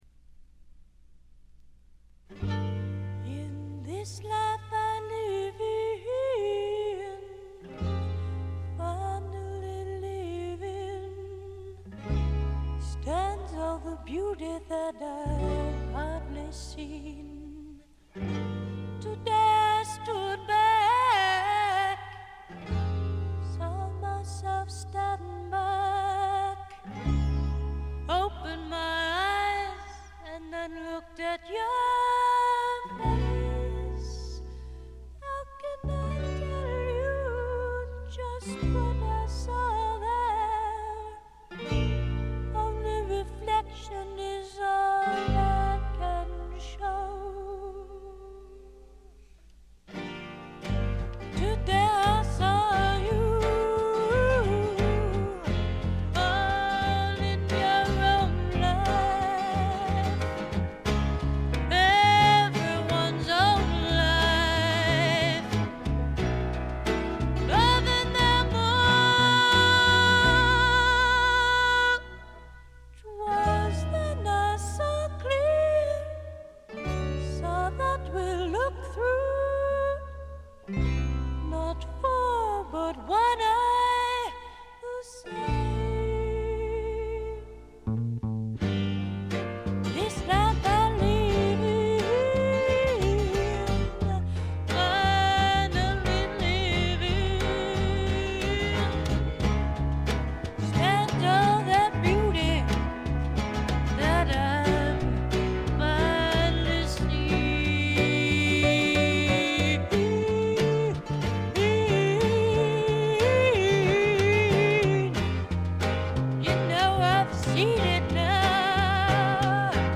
わずかなノイズ感のみ。
試聴曲は現品からの取り込み音源です。
Vocal, Guitar